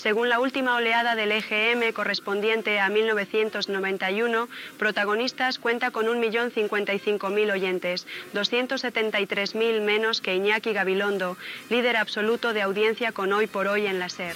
Butlletí de notícies en què s'informa dels programes més ecoltats de la ràdio espanyola segons el darrer EGM ("Hoy por hoy" i "Protagonistas")